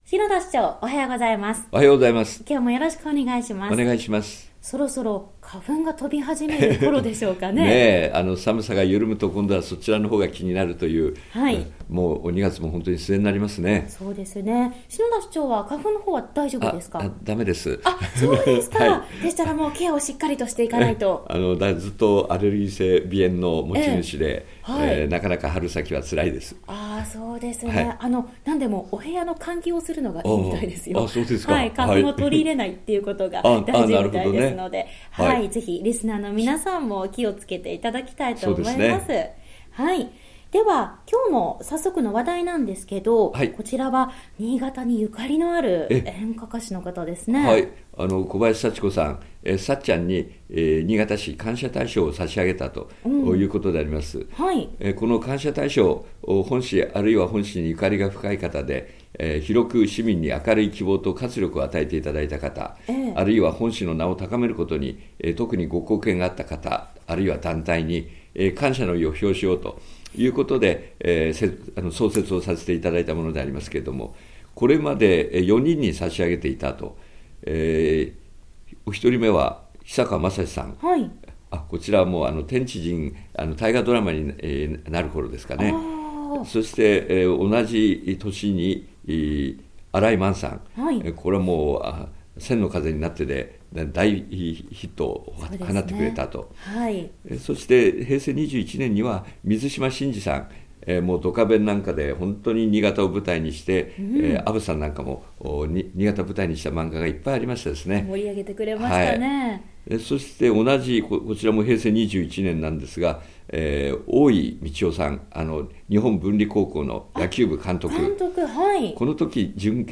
2015年2月27日（金）放送分 | 篠田市長の青空トーク